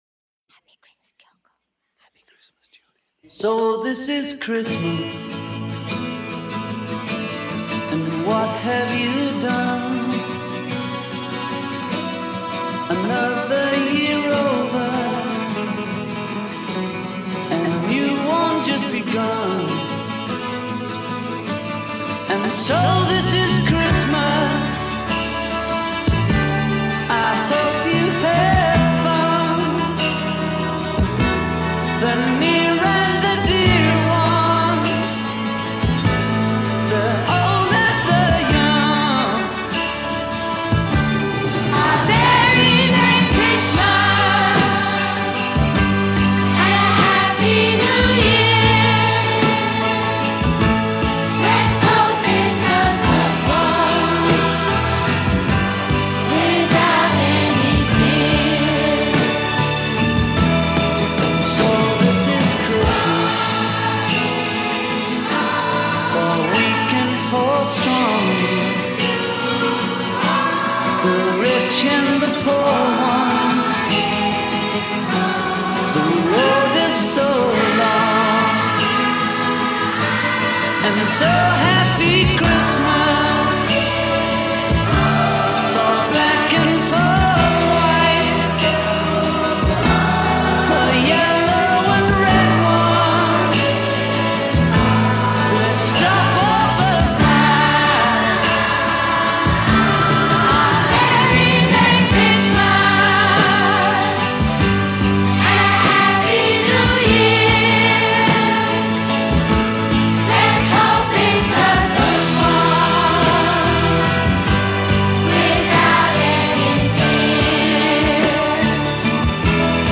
Listen very closely to the super quiet personal intro.